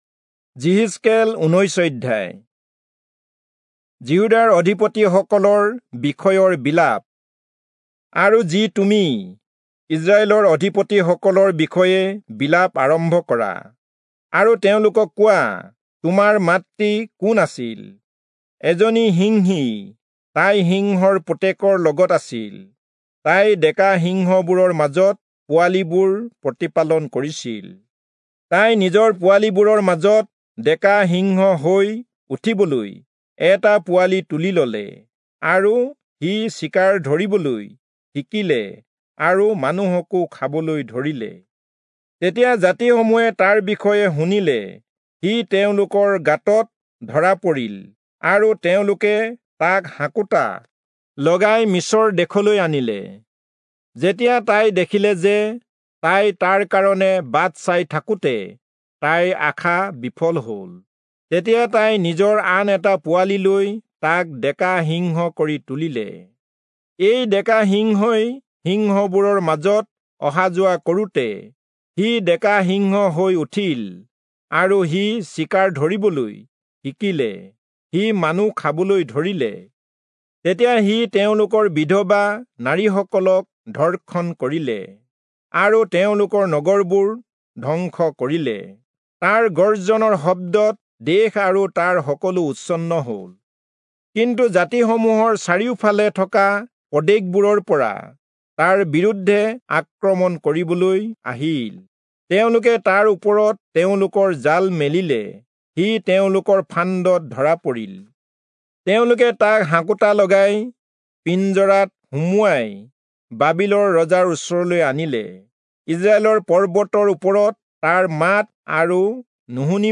Assamese Audio Bible - Ezekiel 1 in Ocvml bible version